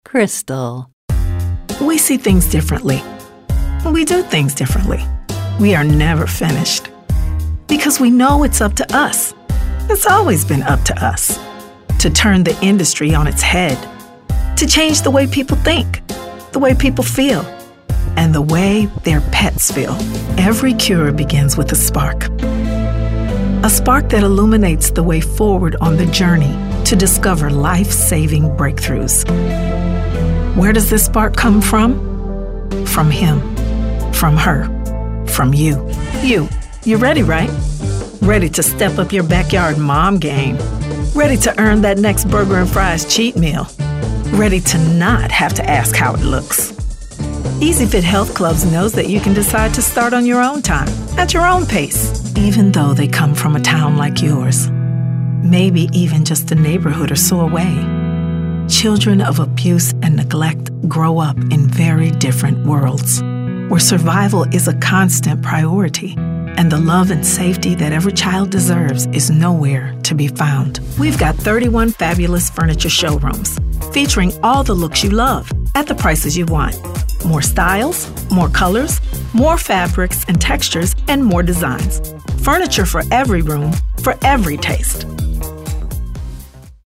soulful and textured and can bring real humanity to any script
Showcase Demo
authoritative, compelling, Gravitas, raspy, serious
anti-announcer, confident, genuine, promo, raspy, upbeat